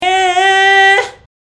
音源①：クリーントーン（普通の歌声）
ガナリではない、普通の歌声をクリーントーン発声と呼んだりしますが、
クリーントーン.mp3